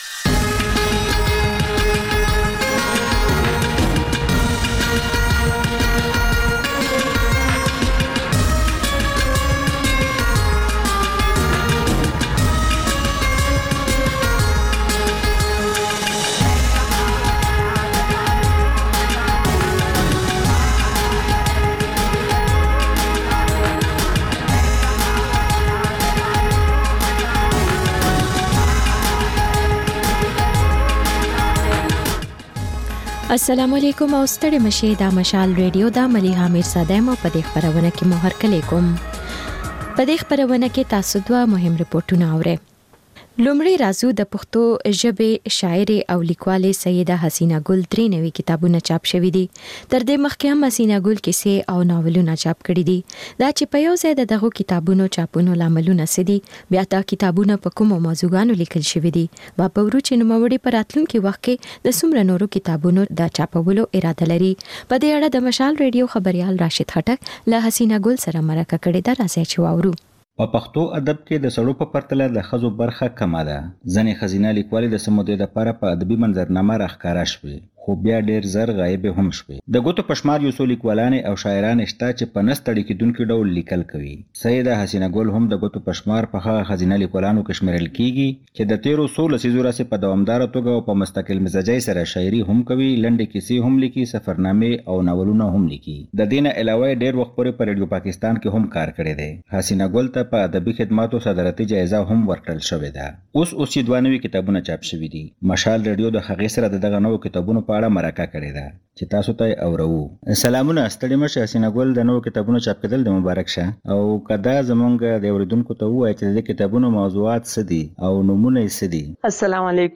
د مشال راډیو ماښامنۍ خپرونه. د خپرونې پیل له خبرونو کېږي، بیا ورپسې رپورټونه خپرېږي.
ځینې ورځې دا ماښامنۍ خپرونه مو یوې ژوندۍ اوونیزې خپرونې ته ځانګړې کړې وي چې تر خبرونو سمدستي وروسته خپرېږي.